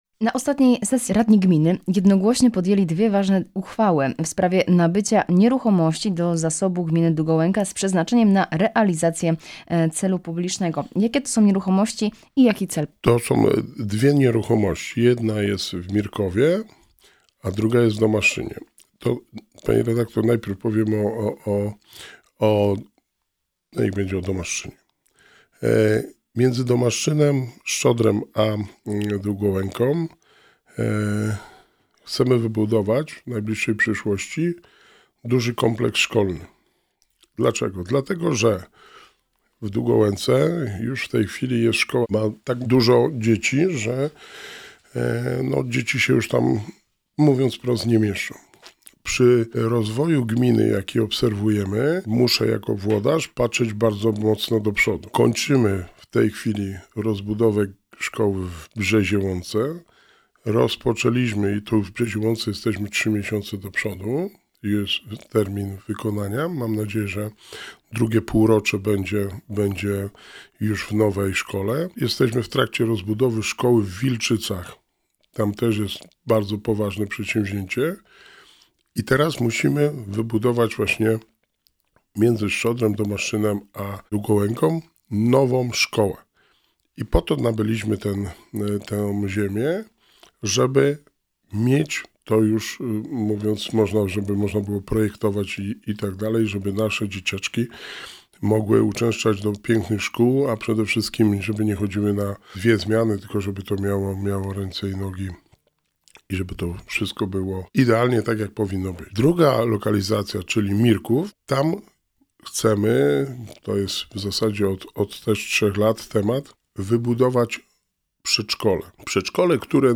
Rozmowa z Wójtem Gminy Długołęka
W studiu Radia Rodzina gościł Wojciech Błoński, wójt Gminy Długołęka. Rozmawiamy o otwarciu Gminnego Ośrodka Kultury, Spotkaniu Opłatkowym połączonym z Jarmarkiem Bożonarodzeniowym, a także o aktualnych i przyszłych inwestycjach.